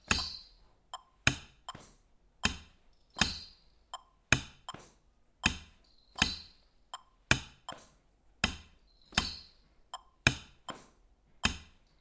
Schritt 2: Nur noch die lauten klopfen, die leisen unhörbar oder in der Luft.